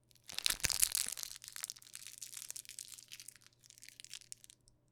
飴の袋をつぶす音
飴の袋をつぶす音.wav